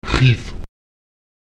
Lautsprecher qic [xiT] hell